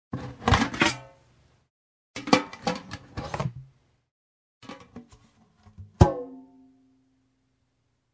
Звуки посуды
Берут тазик и жестяную посуду